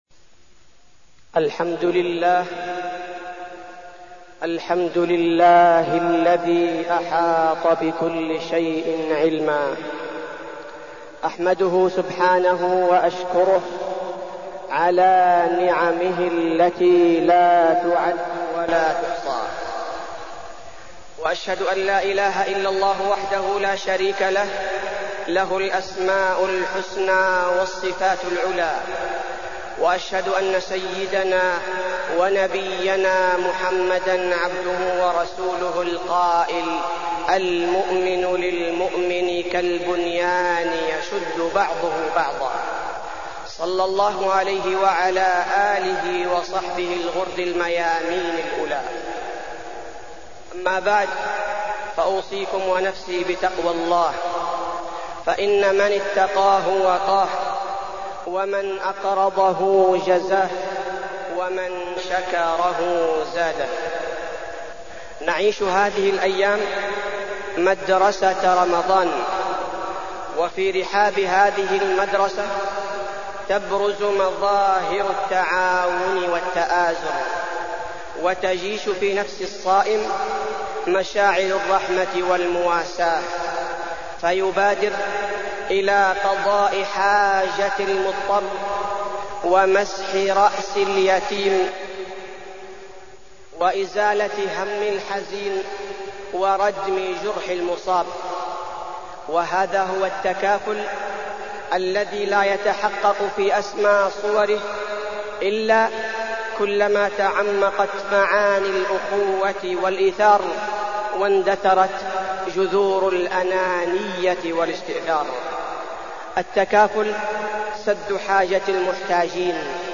تاريخ النشر ١٤ رمضان ١٤١٩ هـ المكان: المسجد النبوي الشيخ: فضيلة الشيخ عبدالباري الثبيتي فضيلة الشيخ عبدالباري الثبيتي التكافل